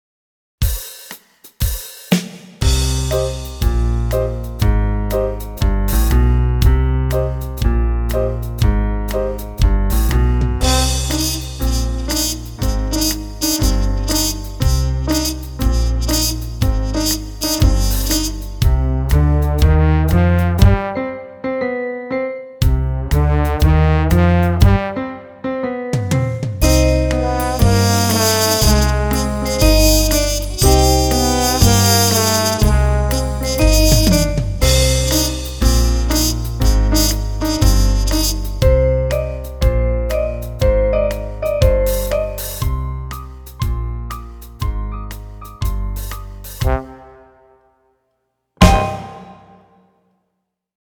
Orchestrated background accompaniments